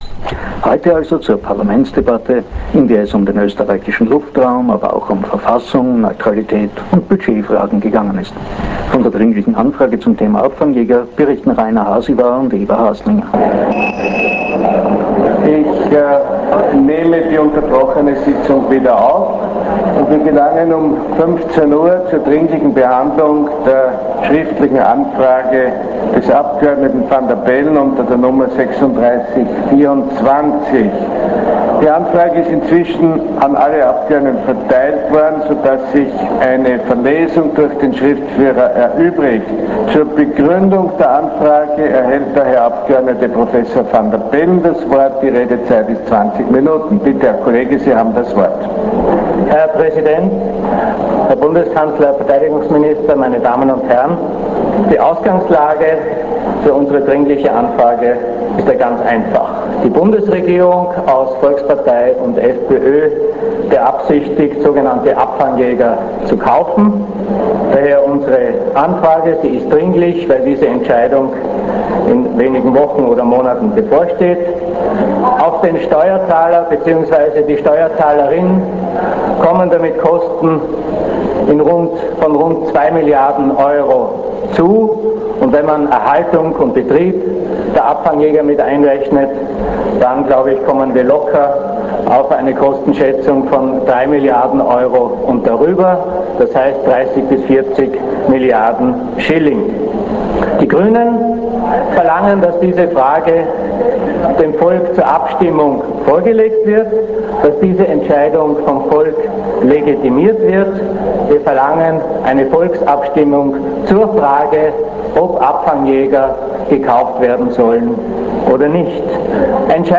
�-1 Journal Panorama vom 20. März 2002 Dringliche Anfrage der Abgeordneten Dr. Alexander Van der Bellen, Kolleginnen und Kollegen an den Bundeskanzler betreffend Abfangj�ger Aufzeichnung der Parlamentsdebatte; Redner: Dr. Alexander Van der Bellen (G), BK Dr. Wolfgang Sch�ssel (ÖVP), Dr. Josef Cap (SPÖ), BM Herbert Scheibner (FPÖ) real audio, 3.431kb, 27:56 Min.